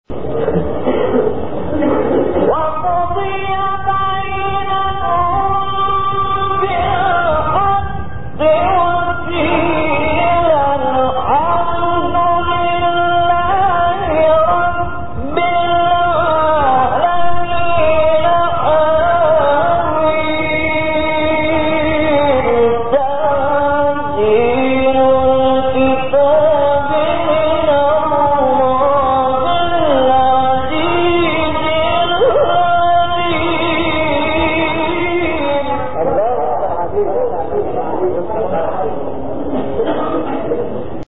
گروه شبکه اجتماعی: مقاطع صوتی از تلاوت قاریان بنام و برجسته جهان اسلام که در شبکه‌های اجتماعی منتشر شده است، می‌شنوید.